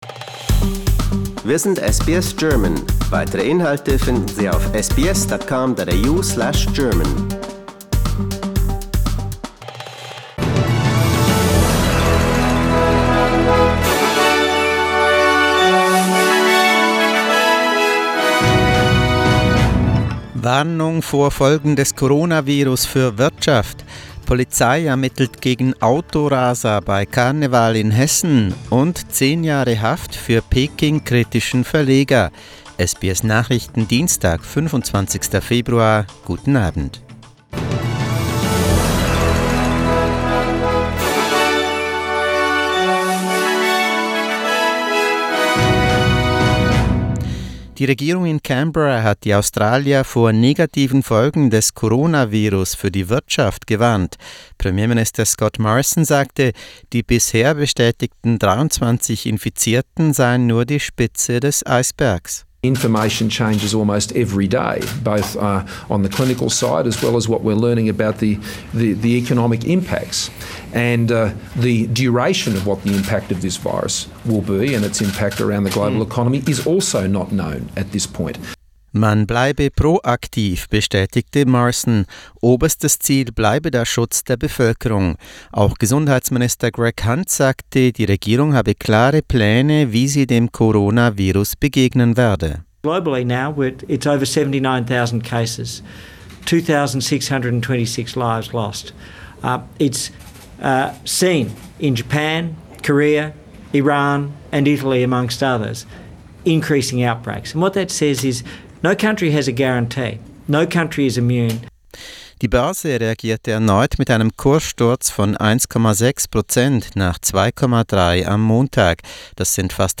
SBS Nachrichten, Dienstag 25.02.20